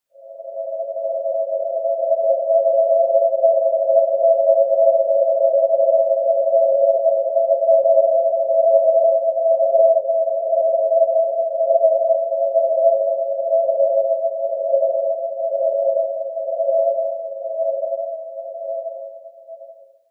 3channel_resonance_oscillator.wma